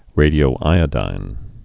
(rādē-ō-īə-dīn)